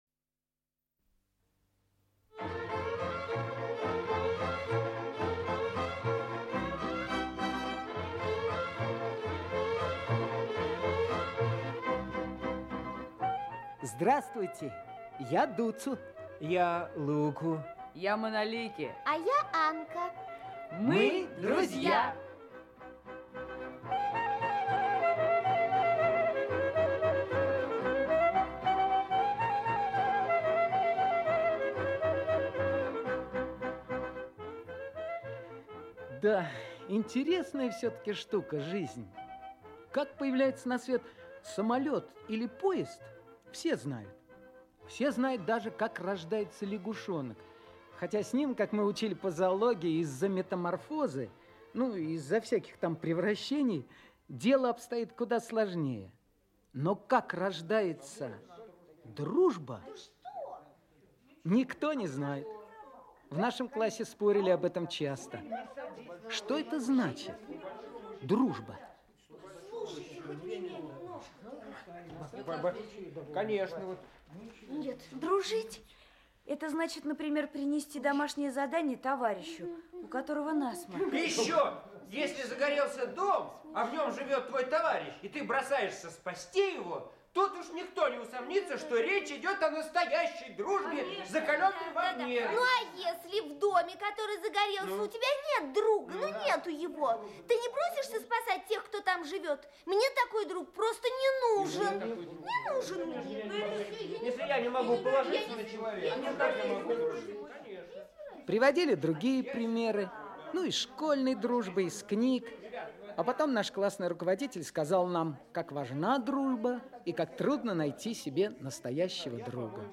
Аудиокнига Дуцу, Луку и весенние каникулы | Библиотека аудиокниг
Aудиокнига Дуцу, Луку и весенние каникулы Автор Эдуард Журист Читает аудиокнигу Актерский коллектив.